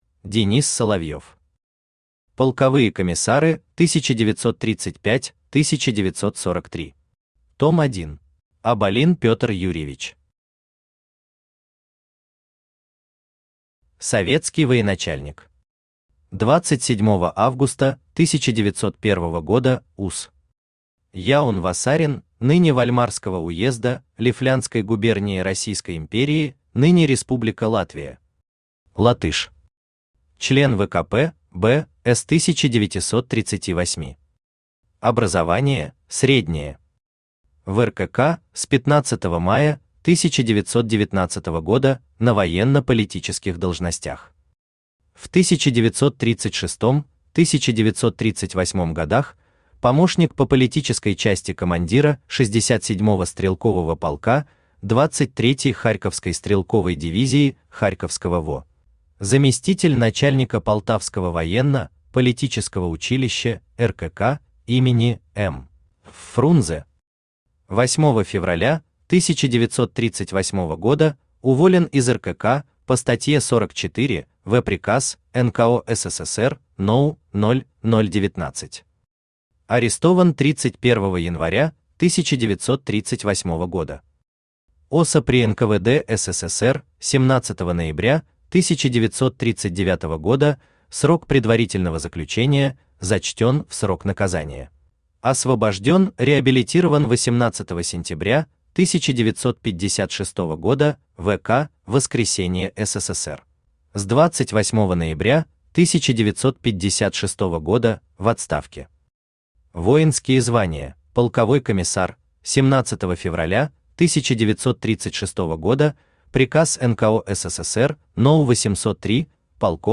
Аудиокнига Полковые комиссары 1935-1943. Том 1 | Библиотека аудиокниг
Aудиокнига Полковые комиссары 1935-1943. Том 1 Автор Денис Соловьев Читает аудиокнигу Авточтец ЛитРес.